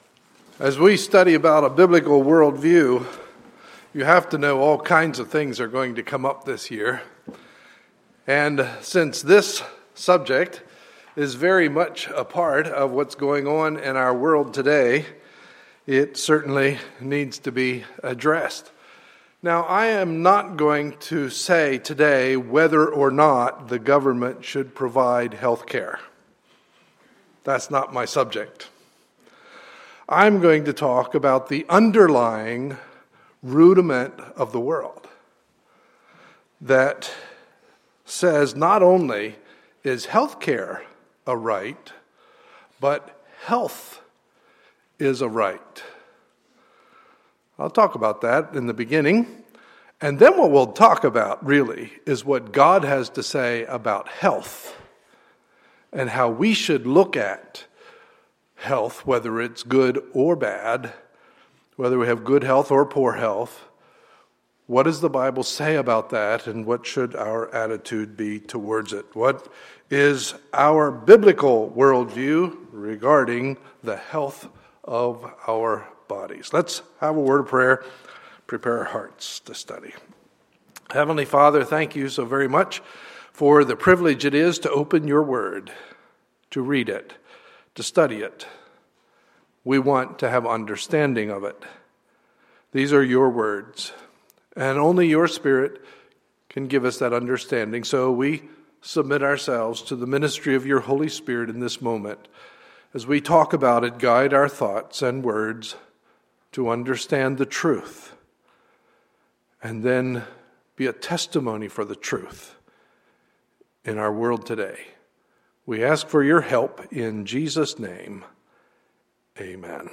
Sunday, March 16, 2014 – Morning Service